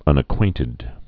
(ŭnə-kwāntĭd)